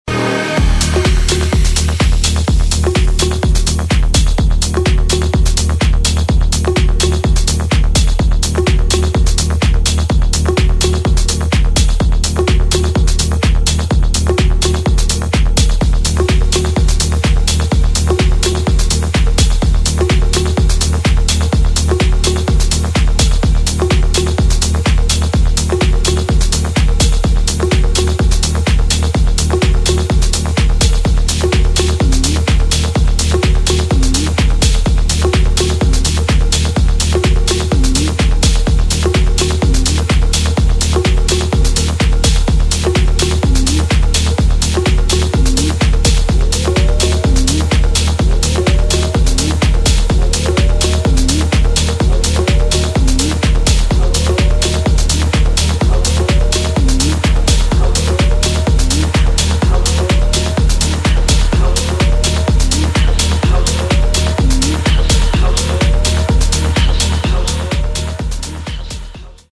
Género: Electronic
Estilo: Hard TranceTrance